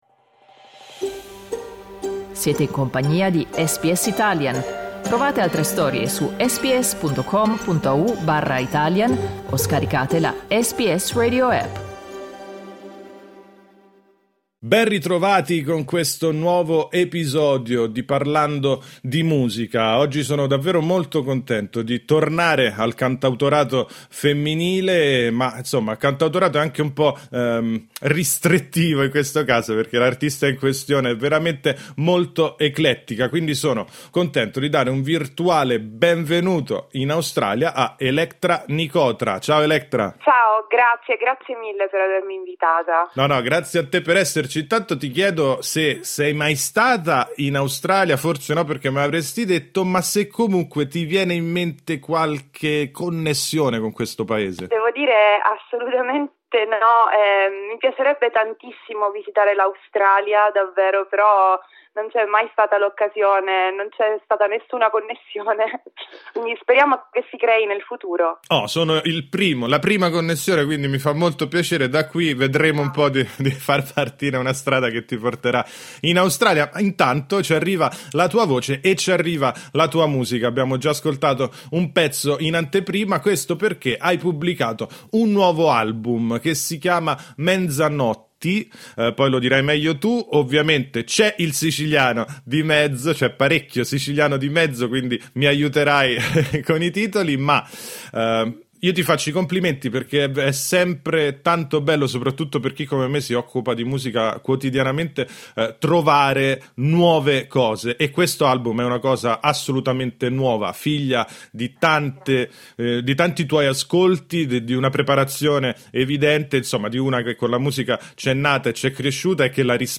Seleziona il player in alto per ascoltare l'intervista Ascolta SBS Italian tutti i giorni, dalle 8am alle 10am.